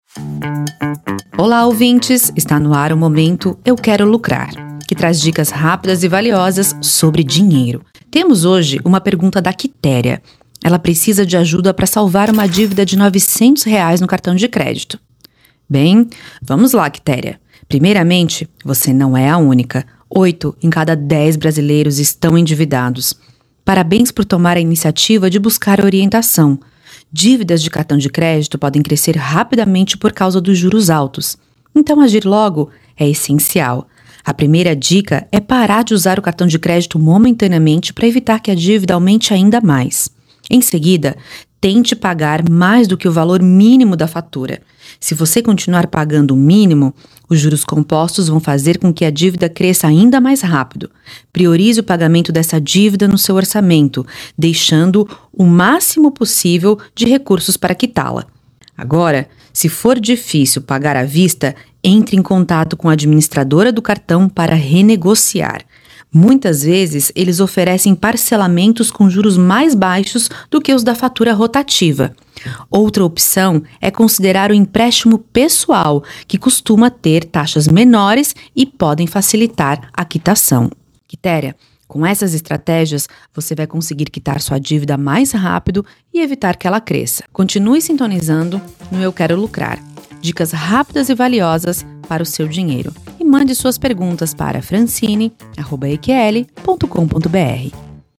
Categoria: Coluna
Periodicidade: 5 vezes por semana (segunda a sexta-feira), gravada